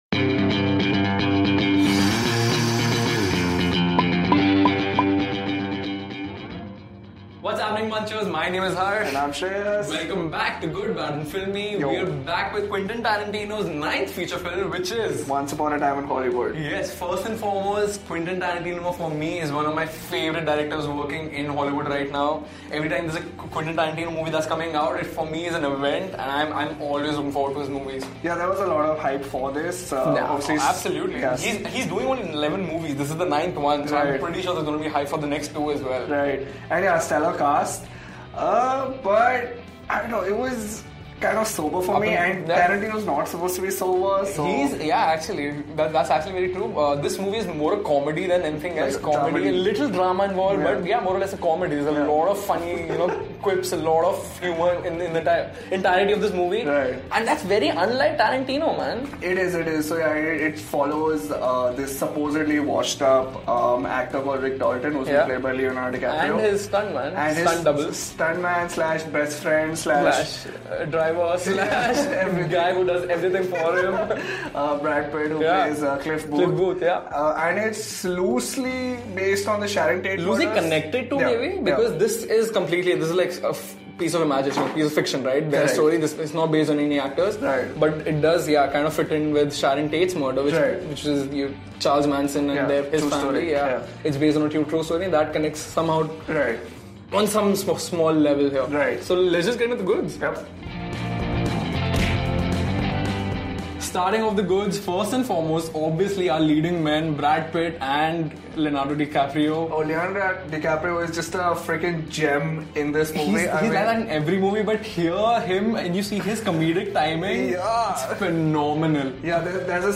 Once Upon A Time In Hollywood Review | Good Bad and Filmy